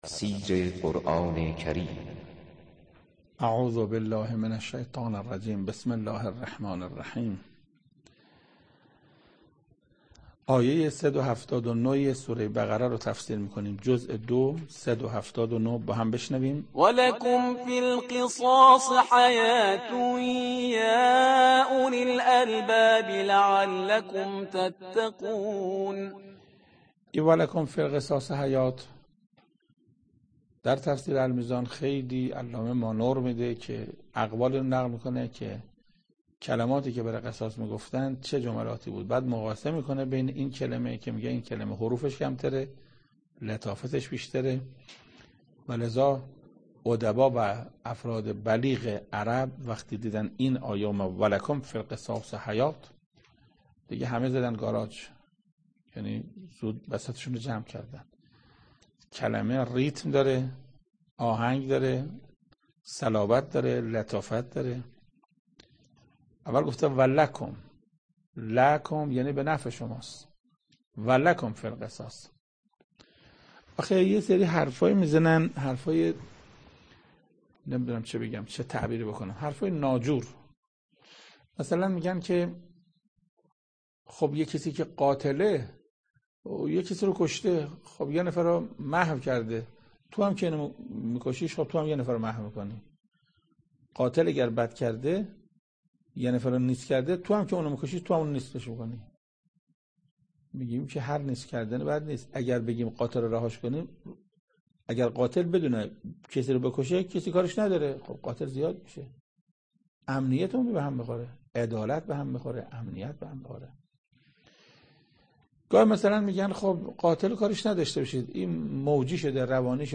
تفسیر صد و هفتاد و نهمین آیه از سوره مبارکه بقره توسط حجت الاسلام استاد محسن قرائتی به مدت 10 دقیقه